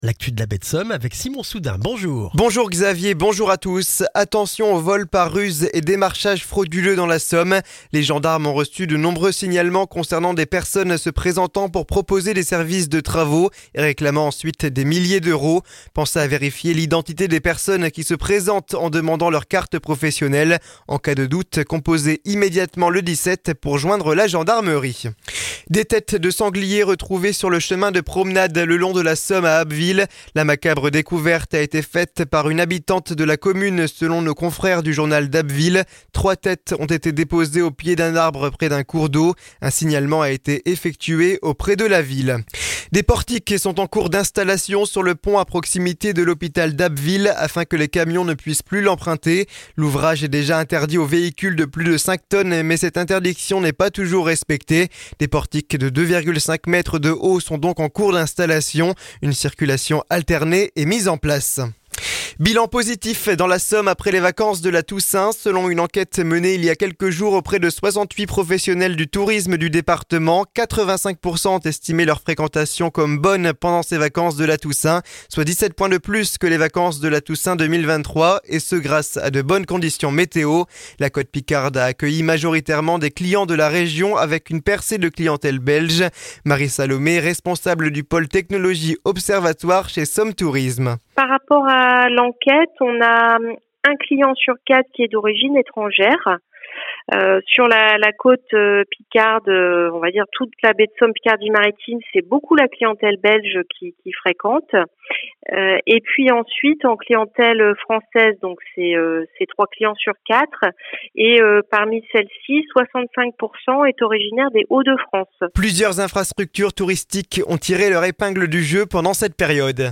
Le journal du jeudi 14 novembre en Baie de Somme et dans la région d'Abbeville